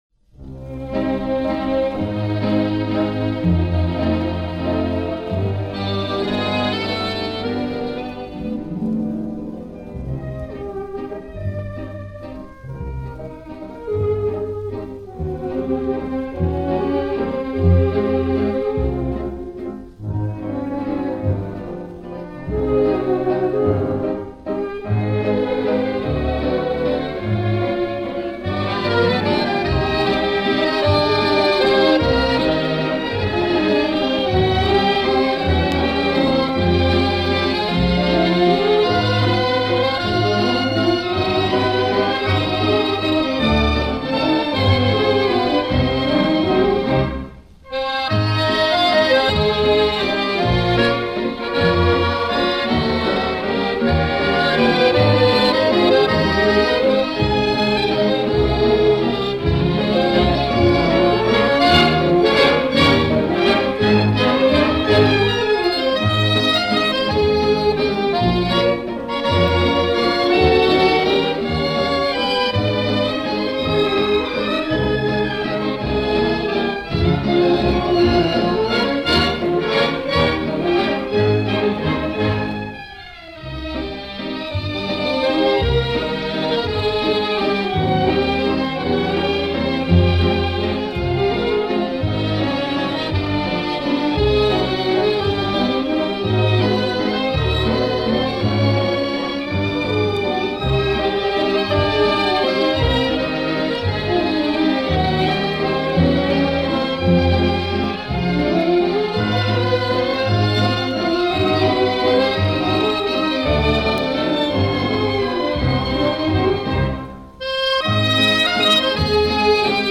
От произведения веет оптимистичным лиризмом.